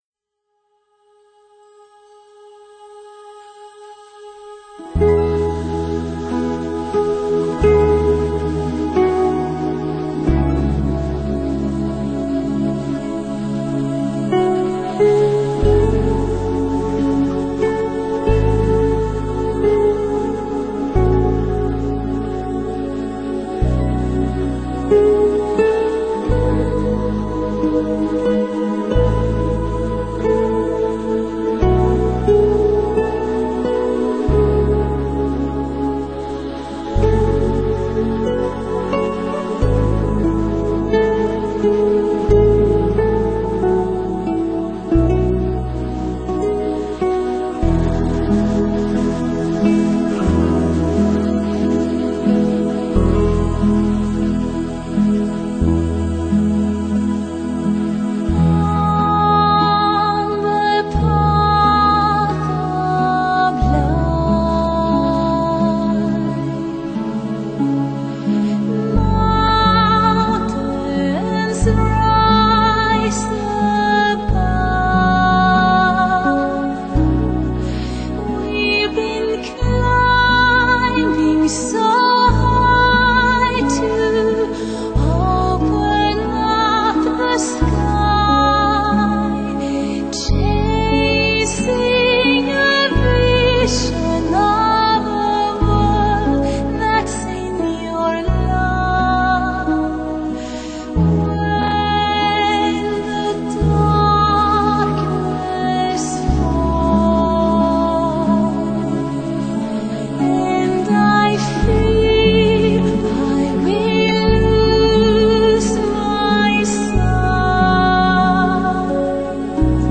[2005-8-1]绝美的歌声，悠扬的旋律